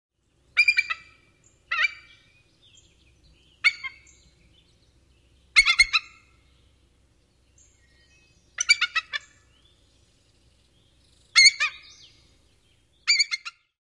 Bald Eagle
Voice
Eagles makes a sharp, cackling kleek-kik-ik-ik-ik or a lower kak-kak-kak.
bald-eagle-call.mp3